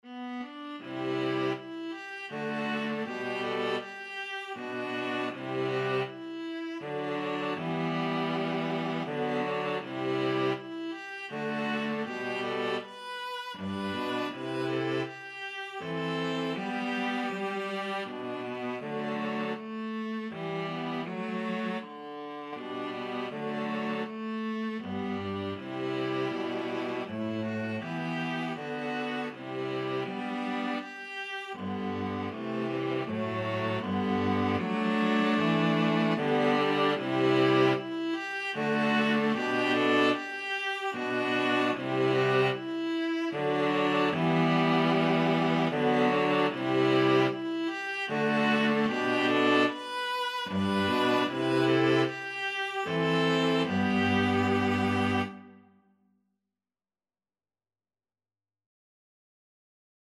Viola 1Viola 2Cello
G major (Sounding Pitch) (View more G major Music for String trio )
3/4 (View more 3/4 Music)
Andante
String trio  (View more Easy String trio Music)
Classical (View more Classical String trio Music)
holst_jupiter_STRT.mp3